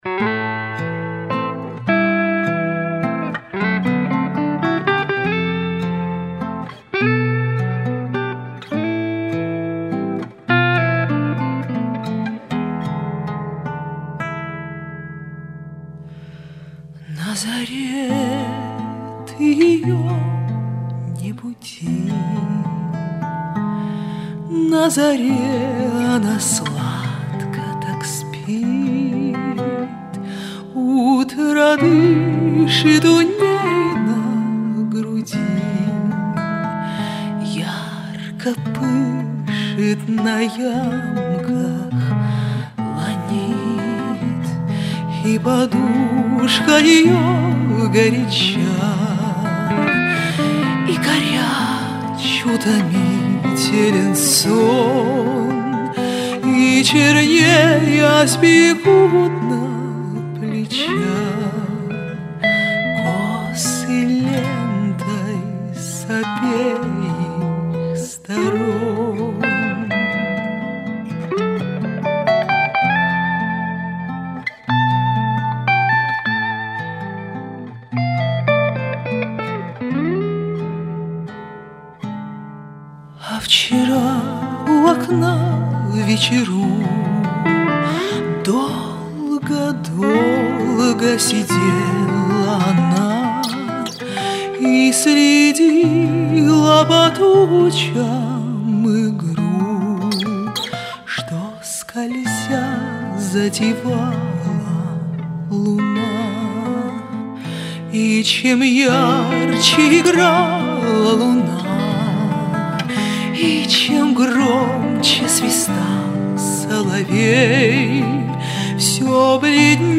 Стихотворение А Блока "Вхожу я в темные храмы" Романс на стихи А. Фета "На заре ты ее не буди" Романс на стихи А. Фета.